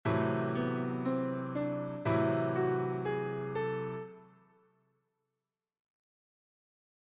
Gamme Mineure Mélodique
La réponse apportée a été la Gamme Mineure Mélodique ( ou gamme mineure-majeure ), qui élève la sixte.
• En LA :
mineur_melodique_la.mp3